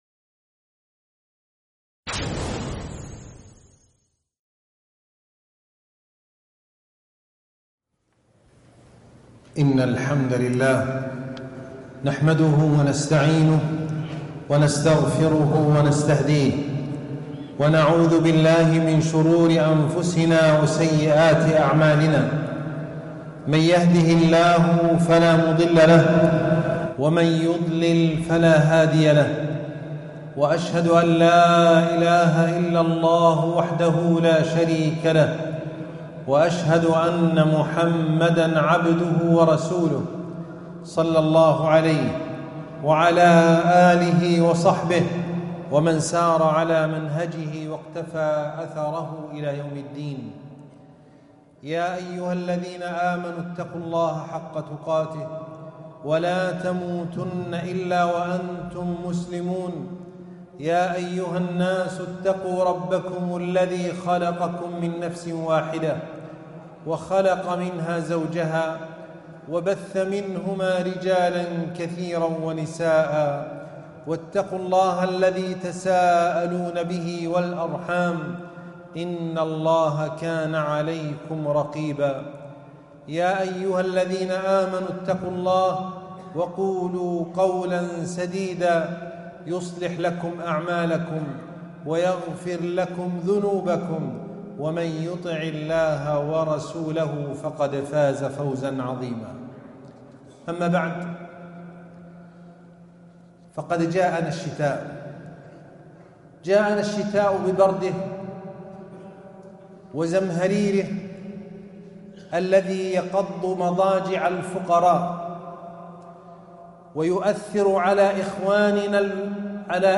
رسائل الشتاء - خطبة الجمعة